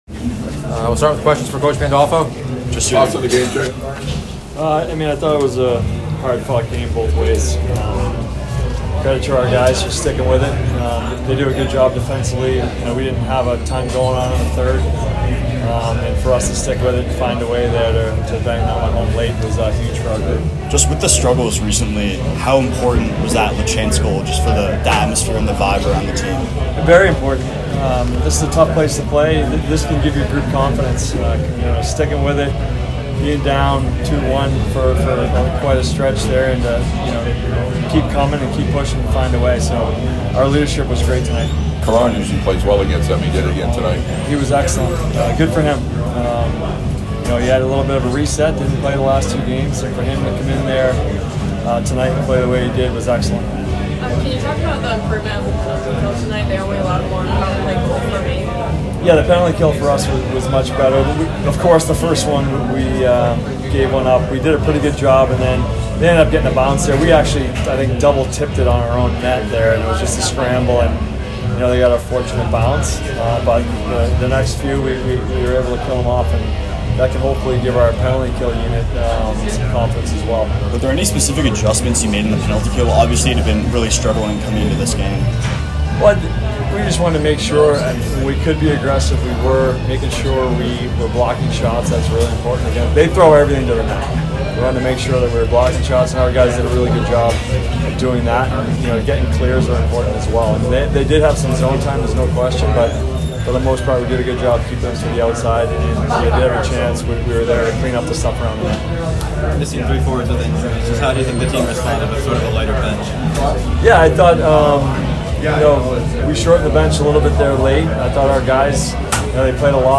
Men's Hockey / Maine Postgame Interview